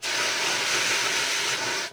extinguisher.wav